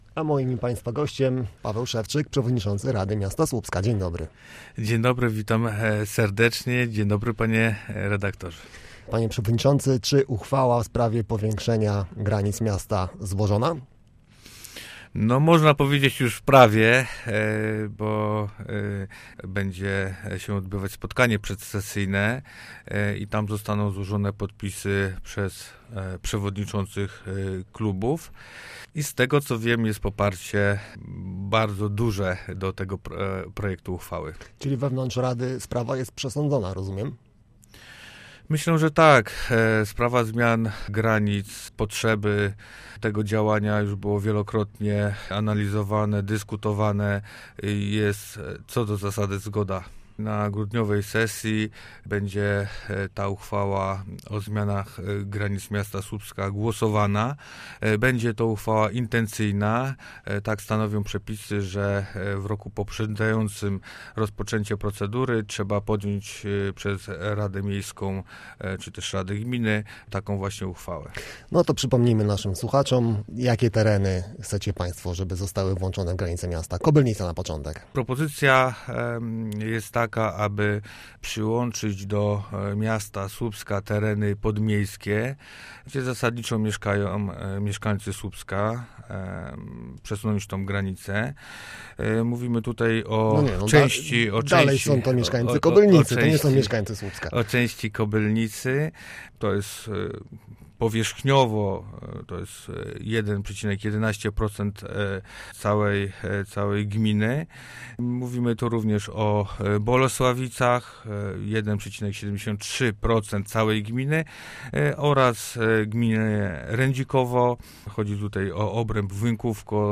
– Sprawa zmian granic była analizowana i dyskutowana wielokrotnie. Co do zasady wśród radnych jest na to zgoda – podkreślał na antenie Radia Gdańsk Paweł Szewczyk, przewodniczący Rady Miasta Słupska.
Posłuchaj rozmowy z Pawłem Szewczykiem o powiększeniu miasta: